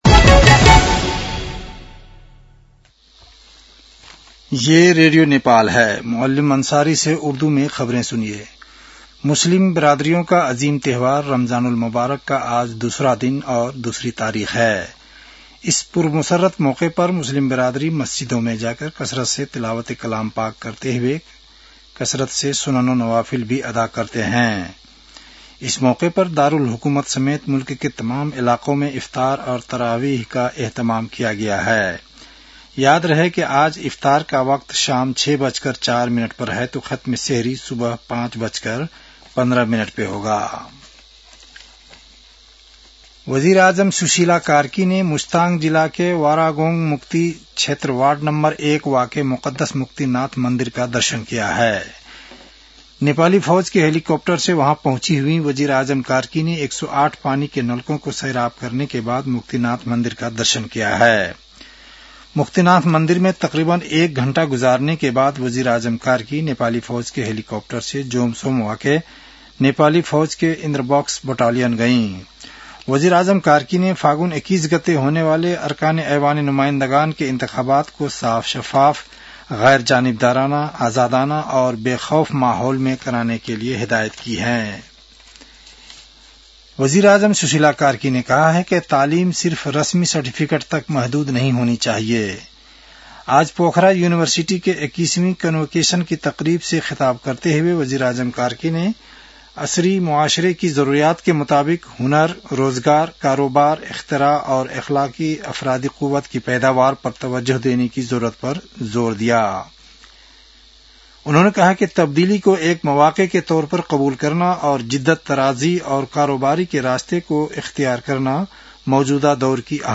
उर्दु भाषामा समाचार : ८ फागुन , २०८२
Urdu-news-11-08.mp3